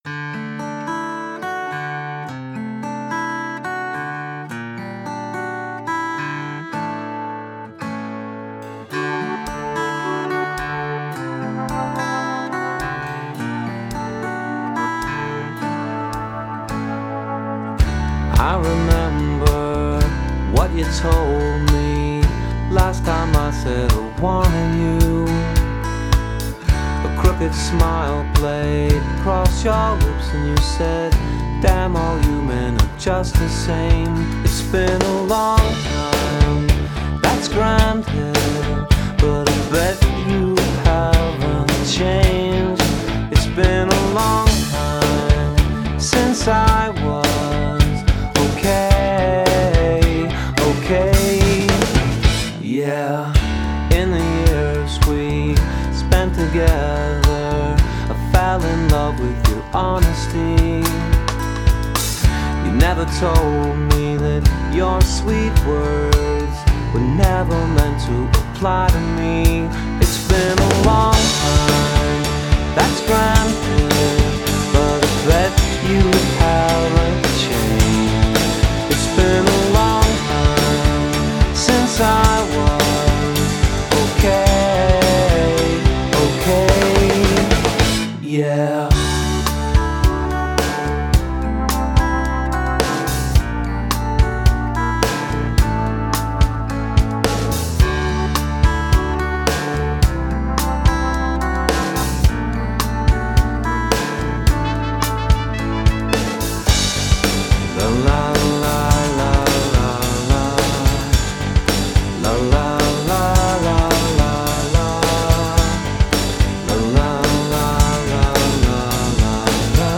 Bluesy-rock is the best way to describe it.
really nice acoustic/rock song